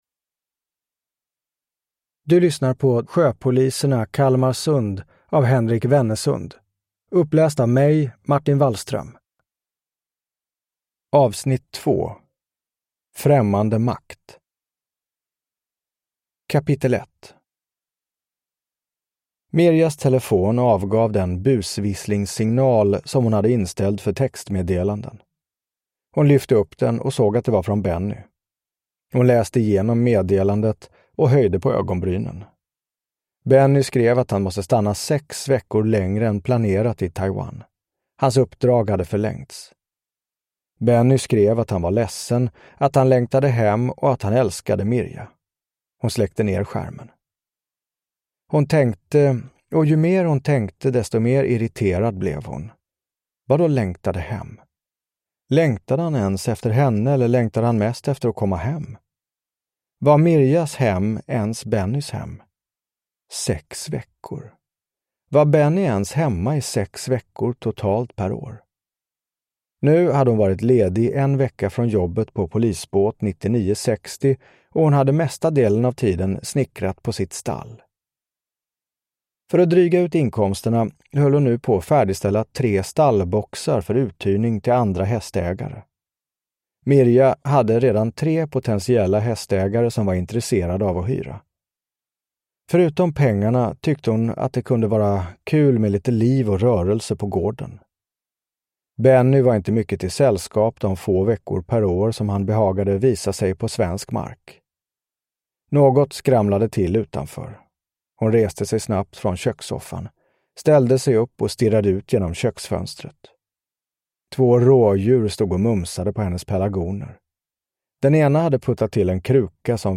Främmande makt (ljudbok) av Henrik Wennesund